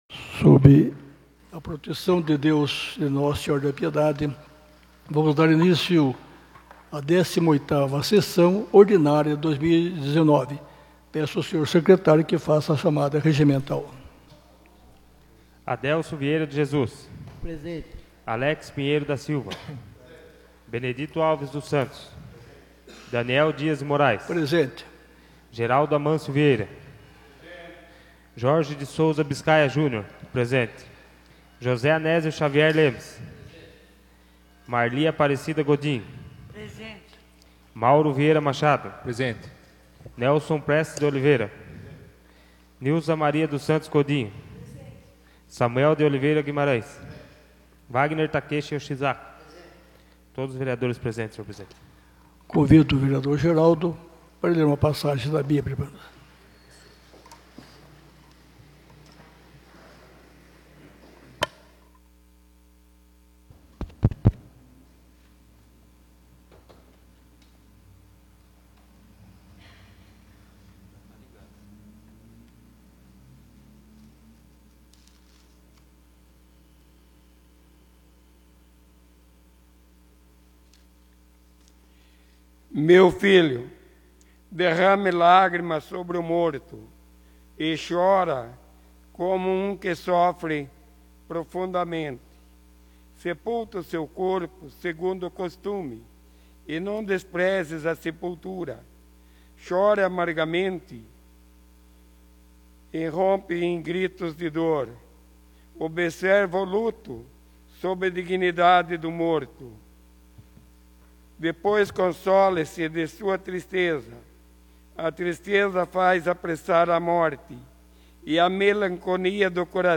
18ª Sessão Ordinária de 2019